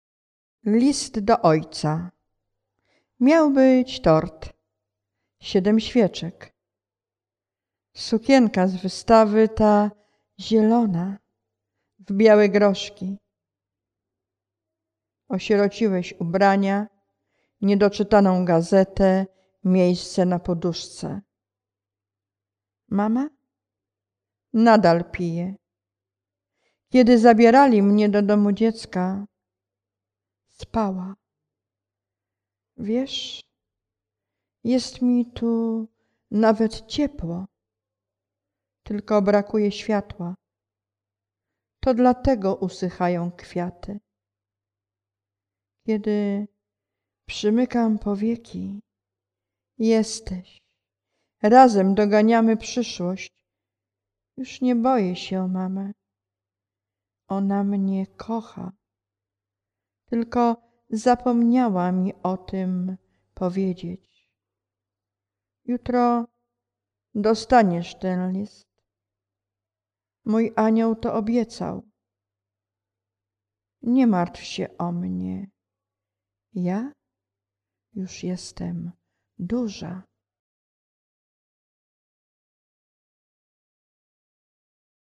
List do ojca (czyta